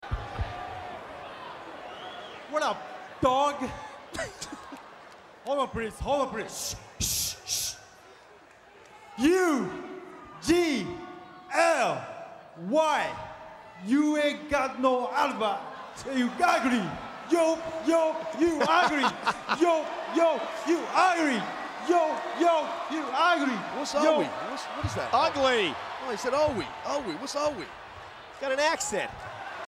Funaki, though, isn’t about to be out done and grabs the mic. You see, he’s going to rap too! He tells us Cena is U-G-L-Y, he ain’t got no alibi!